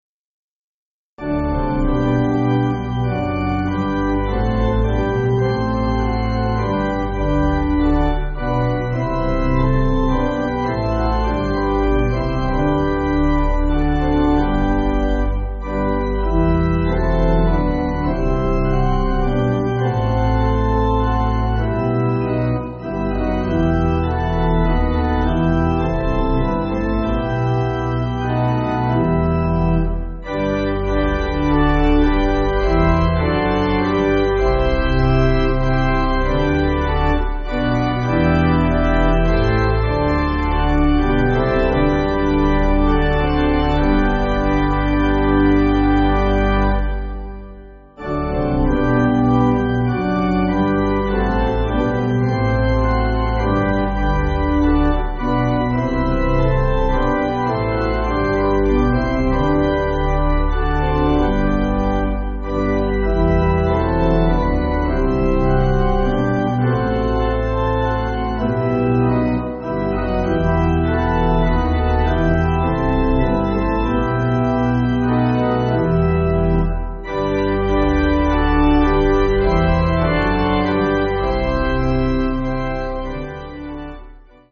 Organ
(CM)   5/G